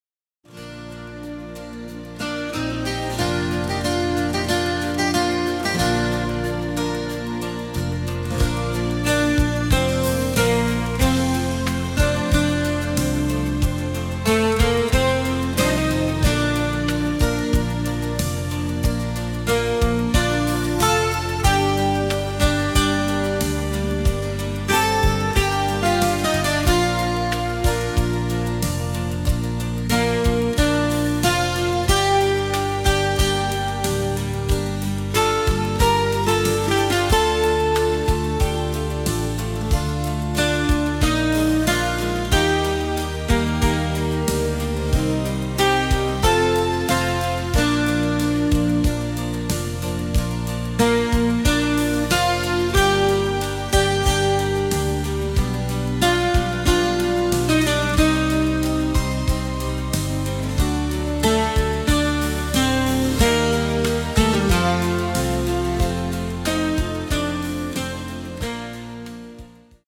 Ruhiges Musikstück
Ein ruhiger Rhythmus, der auch zum Träumen einlädt.